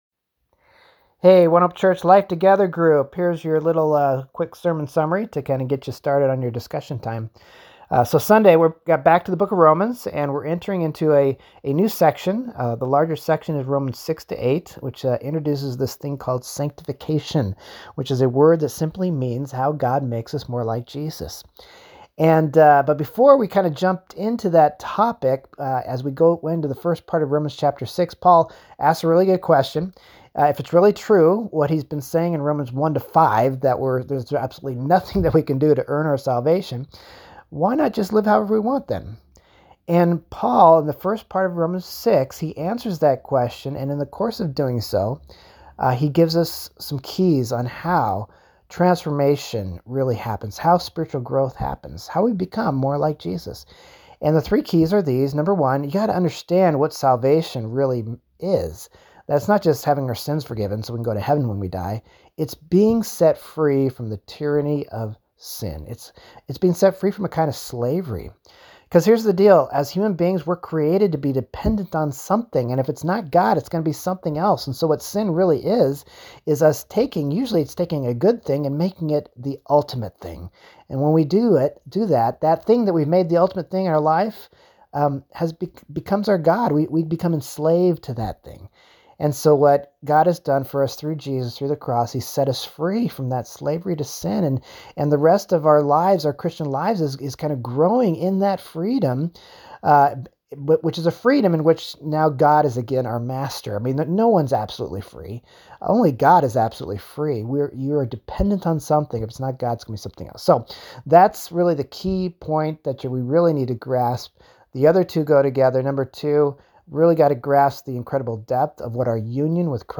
Sermon Recap for Life Together Groups